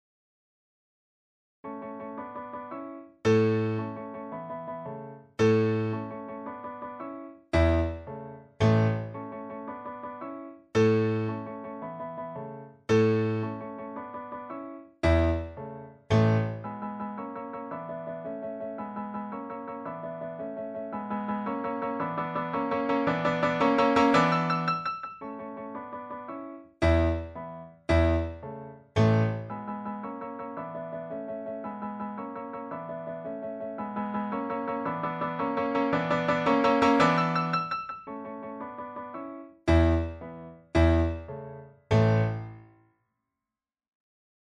Kafka page 200 - Presto pour piano.
"Presto" est une indication portée par Beethoven.
Kafka_Pagina200_PrestoPerPianoforte.mp3